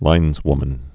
(līnzwmən)